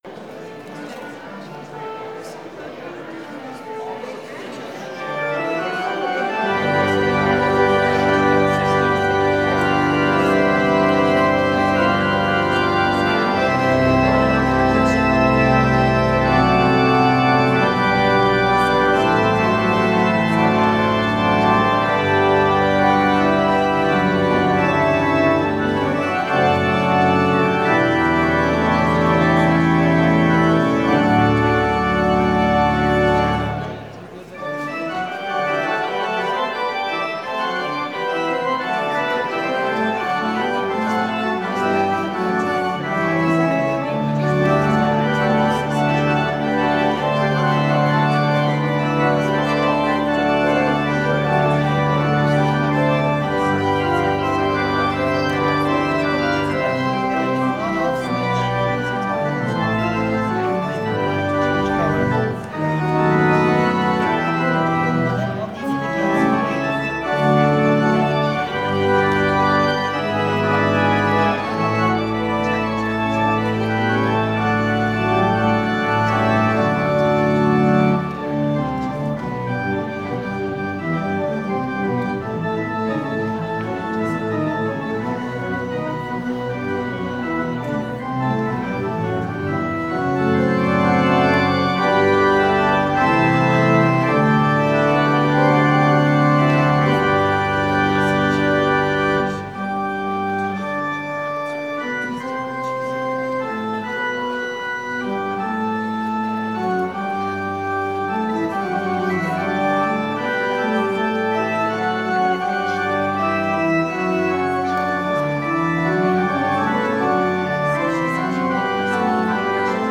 This week in worship we celebrate our second Music Sunday of the year, this time inspired by the life and works of 18th century composer, Wolfgang Amadeus Mozart. Hear, see, and feel how music can convey the human experience and reverence in ways words cannot.